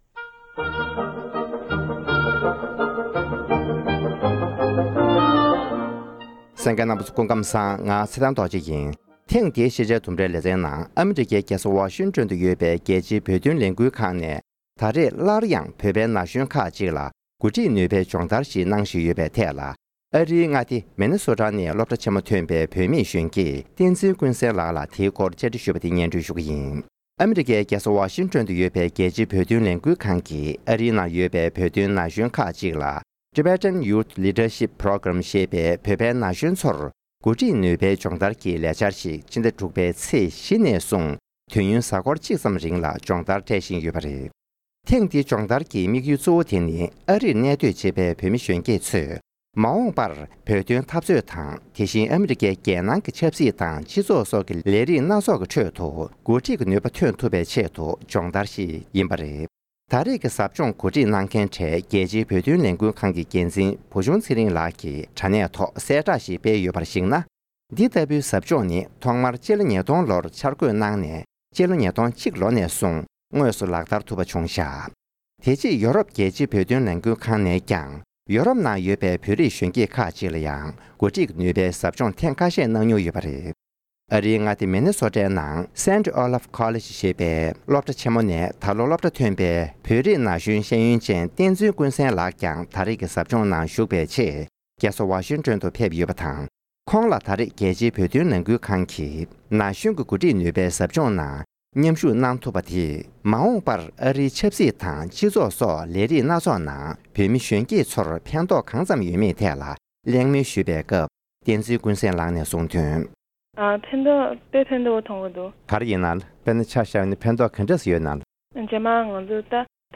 སྒྲ་ལྡན་གསར་འགྱུར།
༄༅༎ཐེངས་འདིའི་ཤེས་བྱའི་ལྡུམ་ར་ཞེས་པའི་ལེ་ཚན་ནང་དུ། བོད་པའི་མི་རབས་རྗེས་མ་ཚོས་ལས་རིགས་ཁག་ལ་འགོ་ཁྲིད་ཀྱི་ནུས་པ་སྔར་ལས་ལྷག་པ་ཐོན་ཐུབ་པའི་ཆེད་དུ། སྦྱོང་བརྡར་གྱི་ལས་དོན་ནང་མཉམ་ཞུགས་བྱེད་ཡོད་པའི་སྐོར། སློབ་གྲྭ་ཆེན་མོ་ཐོན་པའི་བུད་མེད་སློབ་མ་ཞིག་ལ་ཞལ་པར་བརྒྱུད་གླེང་མོལ་ཞུས་པར་གསན་རོགས་ཞུ༎